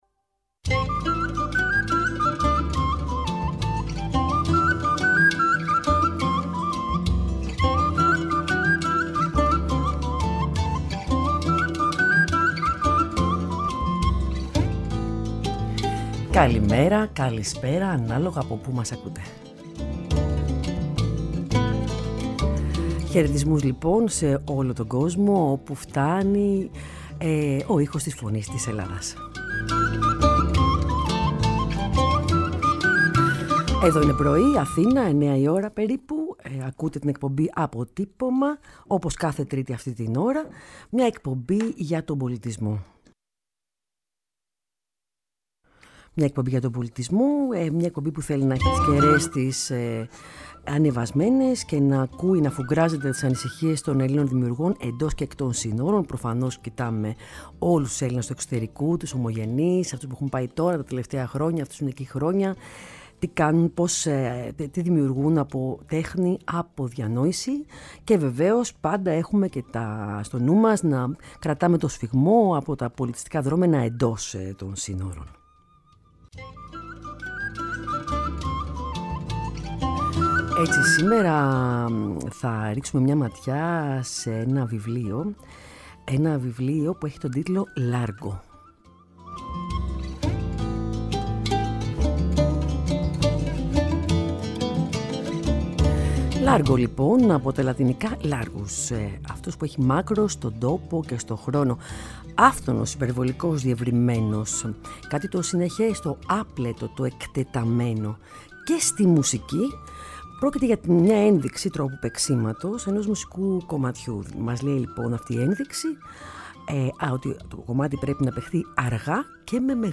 στη συνέντευξή του στην εκπομπή «Αποτύπωμα» της Φωνής της Ελλάδας